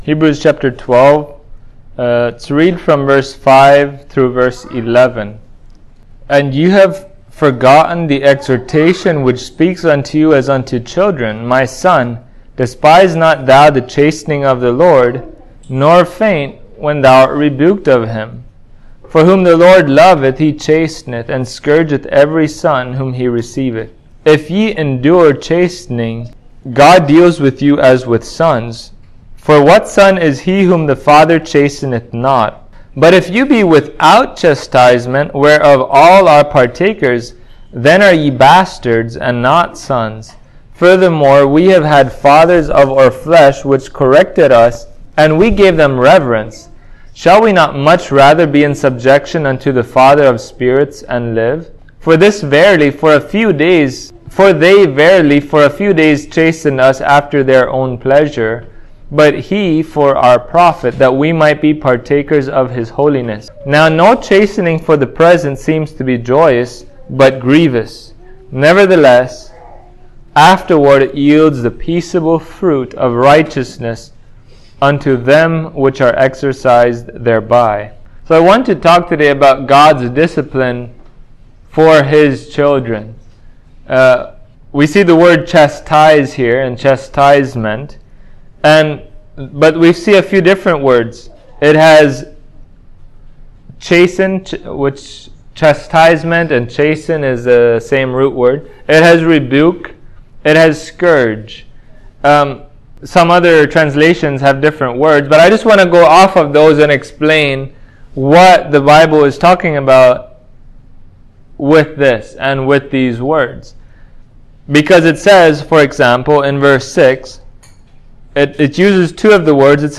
Hebrews 12:5-11 Service Type: Sunday Morning Many Believers misunderstand the discipline of God towards His children.